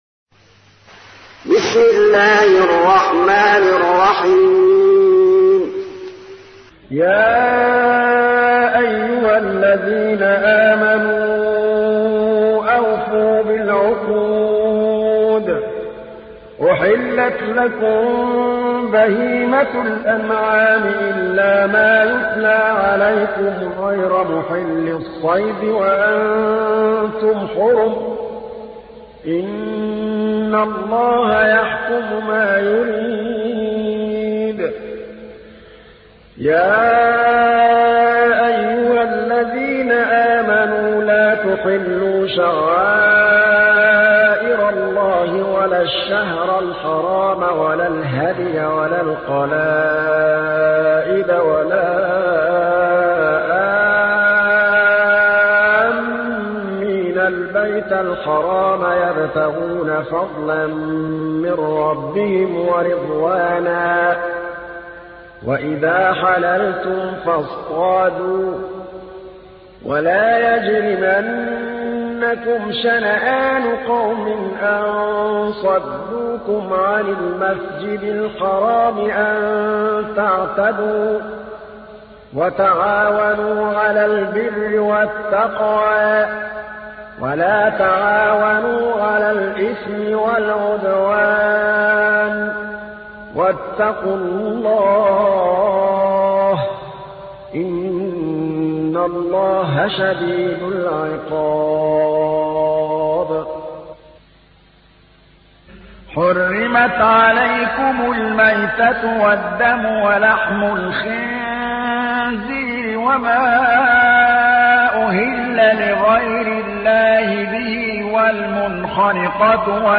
تحميل : 5. سورة المائدة / القارئ محمود الطبلاوي / القرآن الكريم / موقع يا حسين